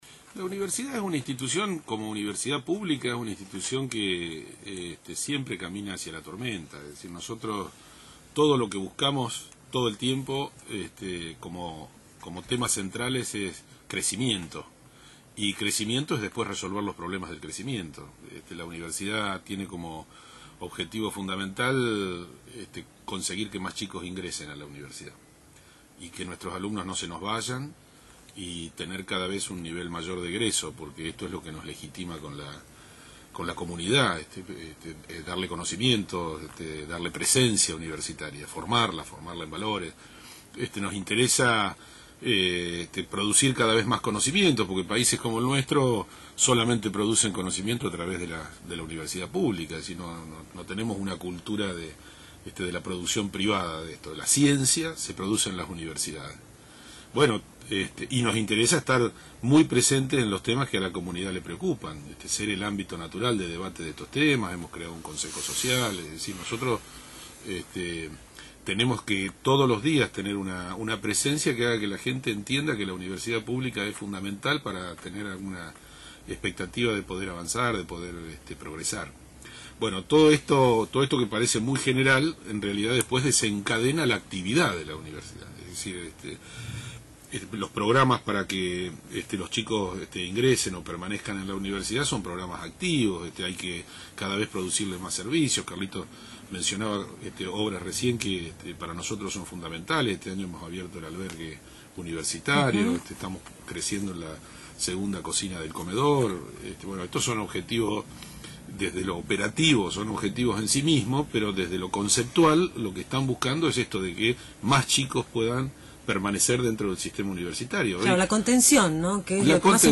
Por: Prof. Dr. Fernando Alfredo Tauber, Presidente de la Universidad Nacional de La Plata